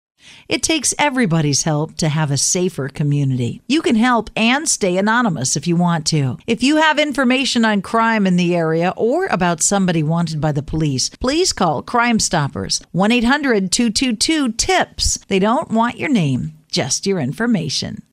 Senator James Lankford offered his remarks on the Senate floor in tribute to the late Dr. Coburn, saying: the Senate is a better place because Dr. Coburn was in it.